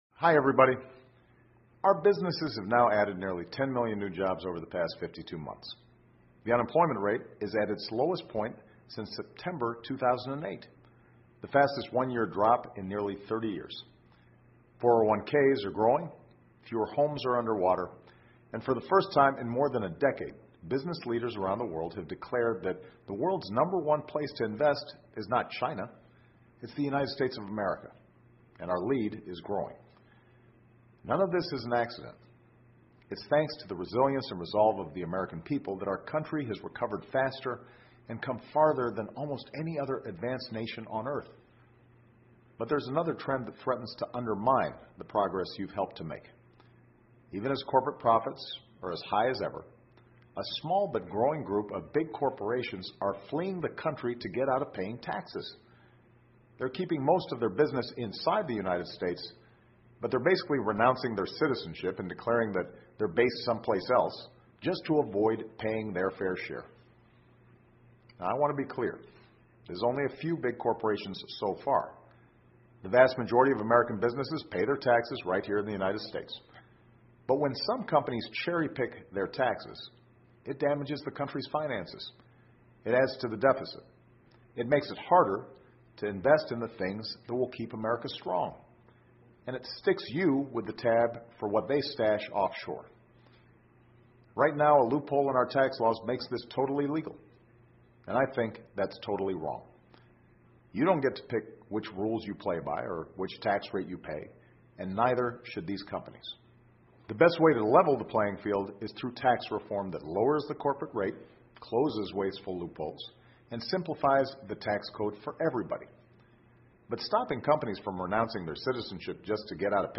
奥巴马每周电视讲话:总统呼吁封堵企业纳税漏洞 听力文件下载—在线英语听力室